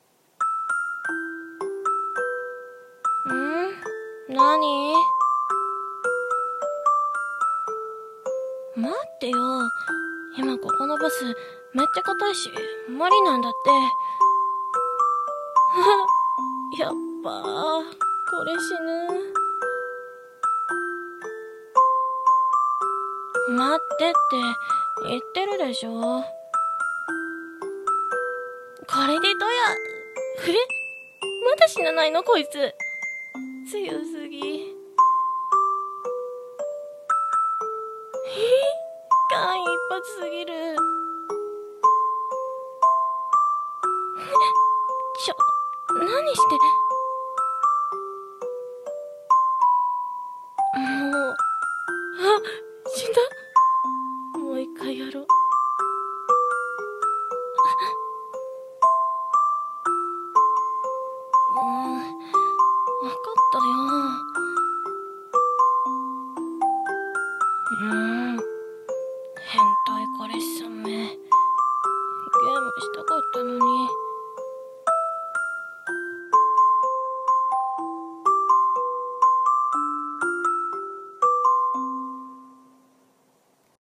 声劇 台本】犬系彼氏のかまちょタイム